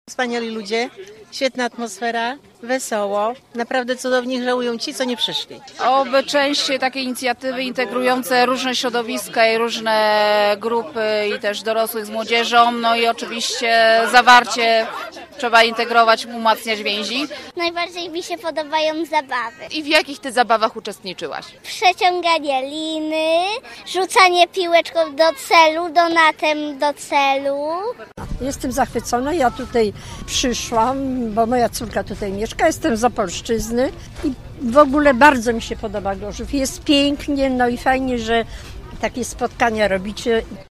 grilujemy-mieszkancy.mp3